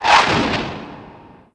1 channel
RocketV1-1.wav